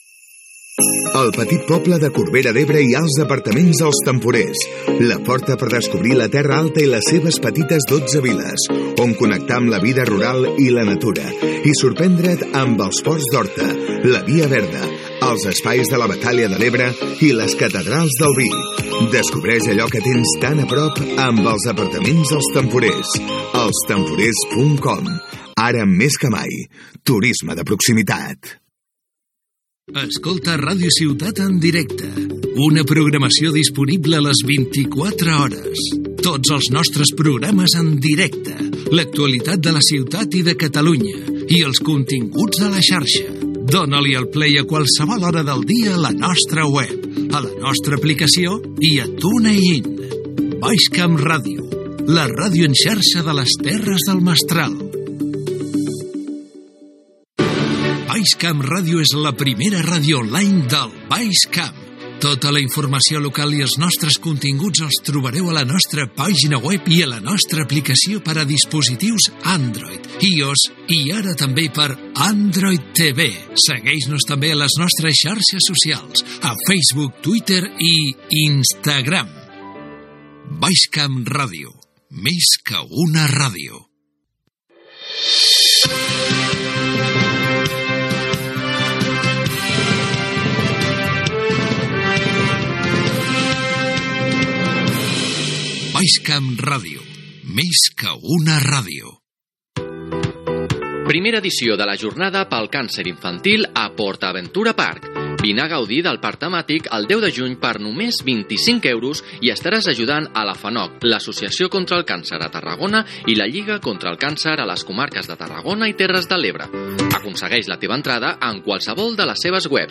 Publicitat, indicatiu de la ràdio i publicitat